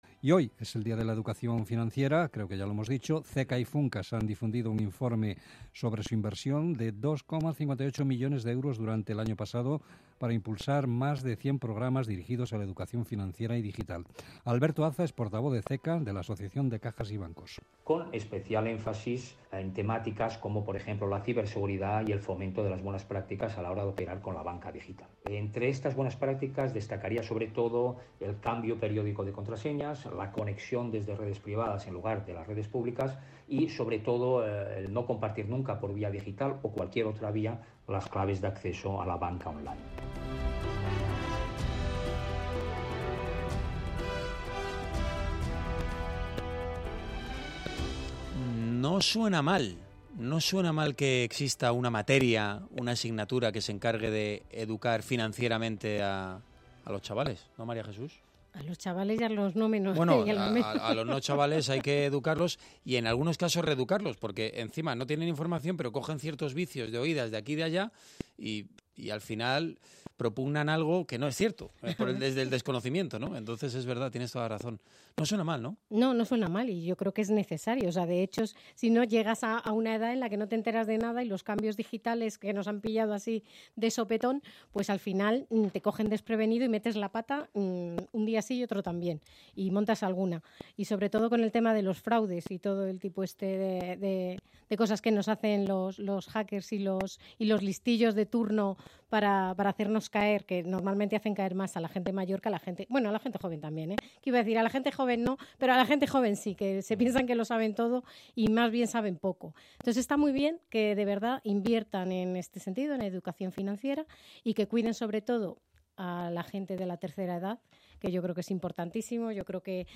ha participado en Onda Madrid para hablar de la inversión que se realiza desde el sector CECA…